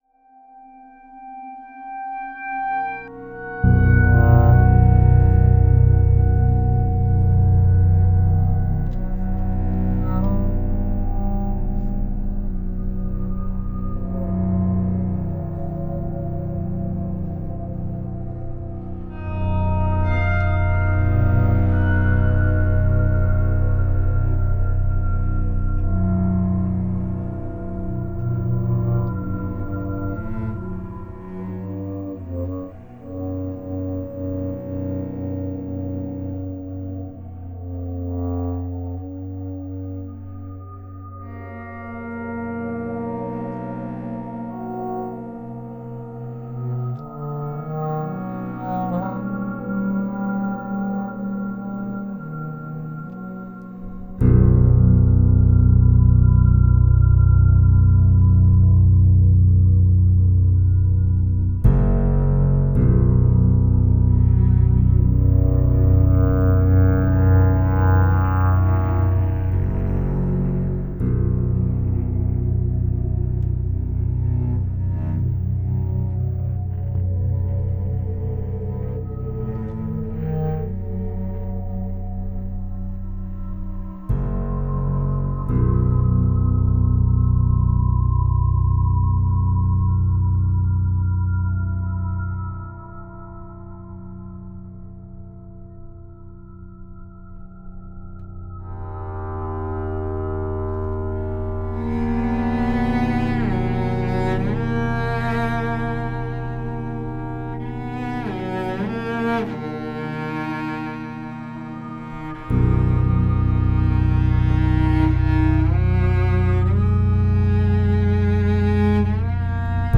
The dark garden is warm, bronze, safe, and heavy, while the light garden is cool, blue, open, and airy.
Bellow is an excerpt from the dark garden characterized by warm harmonies and a heavy low bass.
The program reads the image like a spectrogram and generates a sound-bed for this work. From this ‘sound-bed’ all of the harmonies and melodies are created.
2-channel-dark-l-light-r.wav